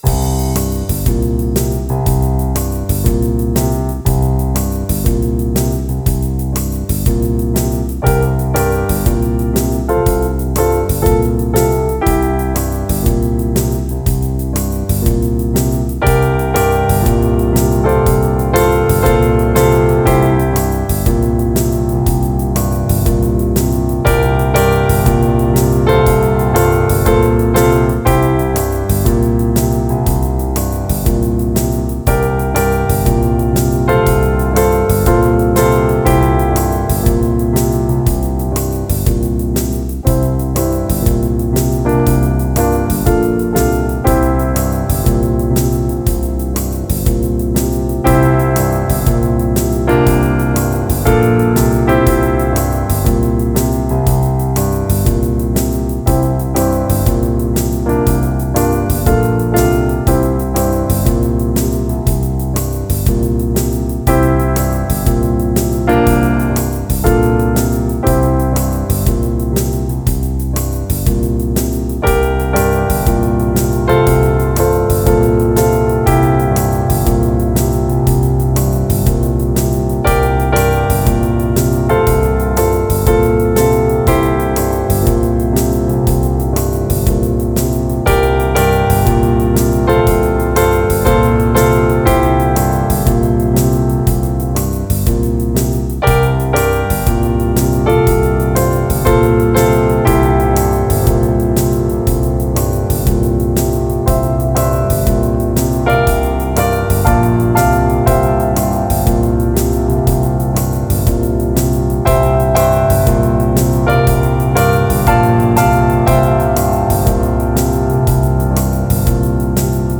Easy listening beatmachine with jazzy piano.
Folk /Creative Commons License 4.0 / noncommercial use free